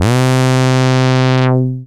RESO ARP.wav